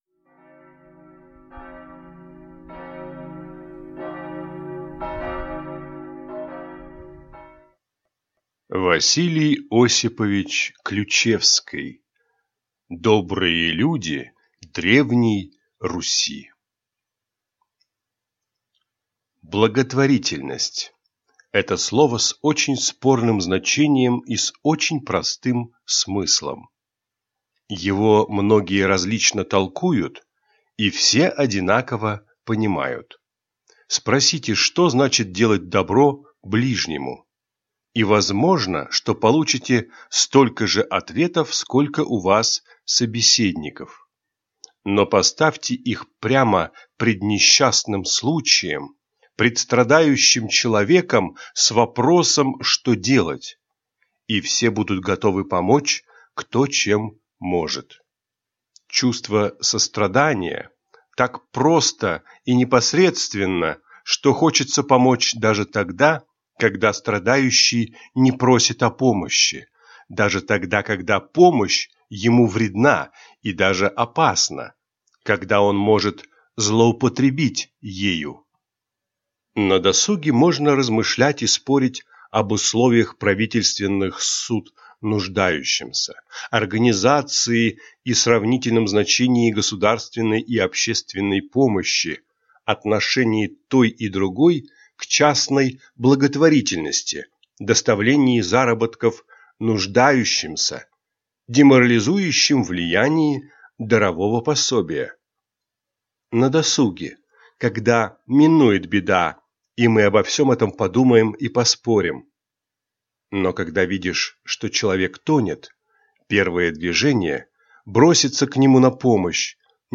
Аудиокнига Добрые люди Древней Руси | Библиотека аудиокниг